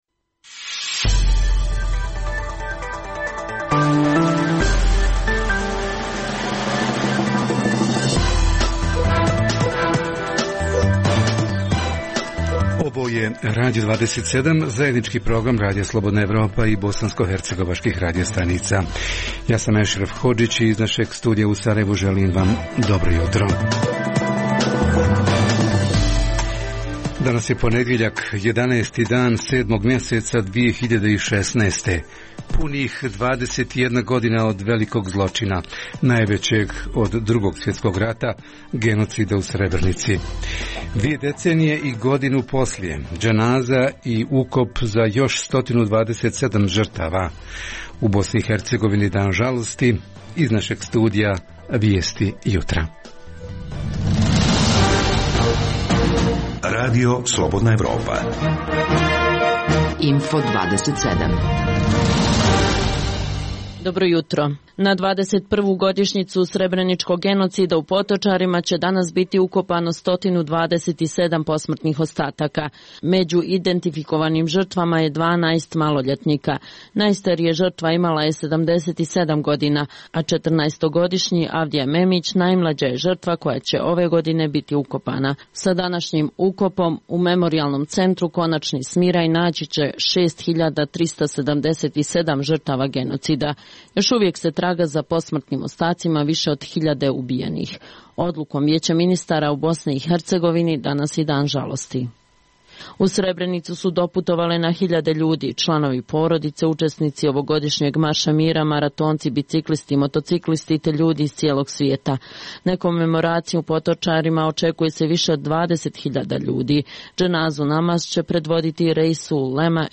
- 21. godišnjica genocida u Srebrenici, u Memorijalnom centru u Potočarma dženaza i ukop još 127 žrtava, u BiH - Dan žalosti. Izravno javljanje iz Memorijalnog centra u Potočarima